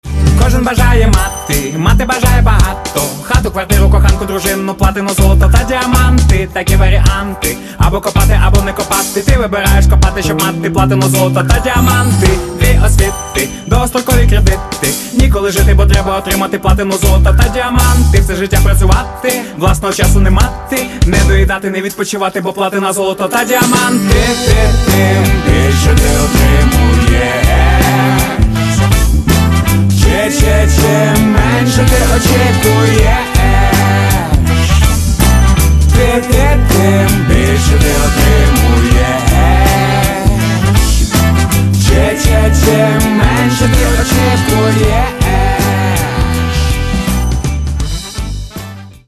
Catalogue -> Rock & Alternative -> Simply Rock